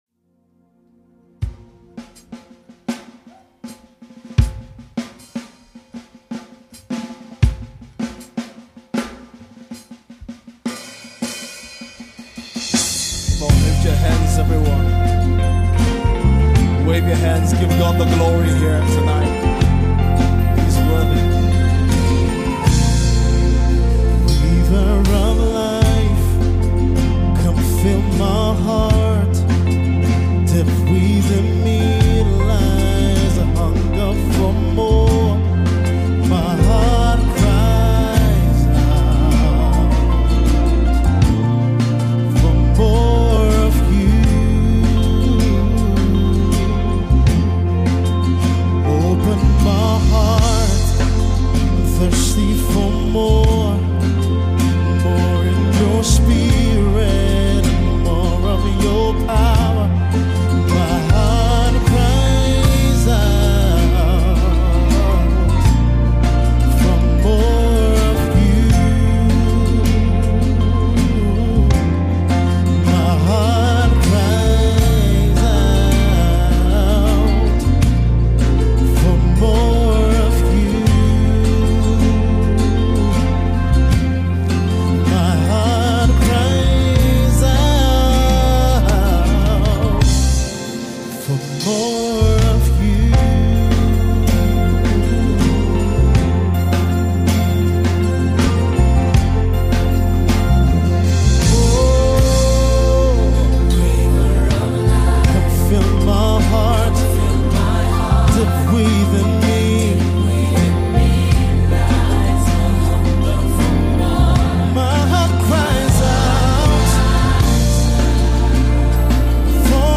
Worship
live worship concert